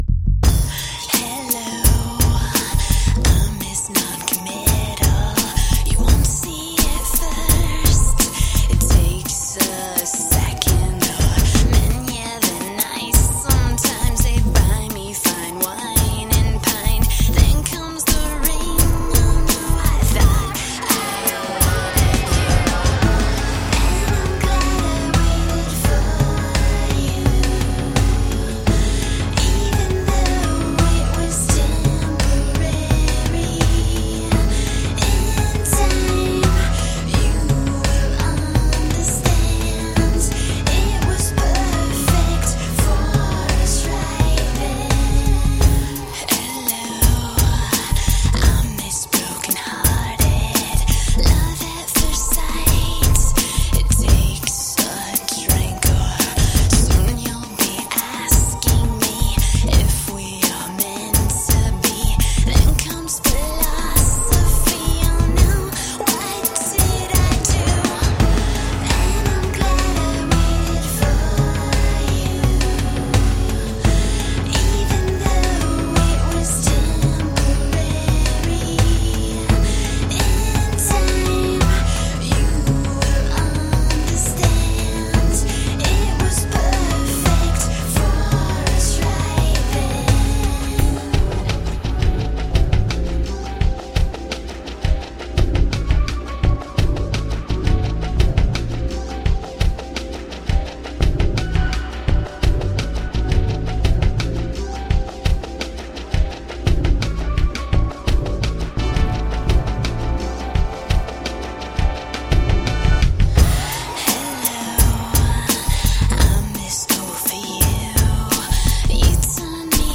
solar powered recording studio in Fiji
Tagged as: Electro Rock, Electronica, Hard Rock